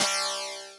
WLR LEAD.wav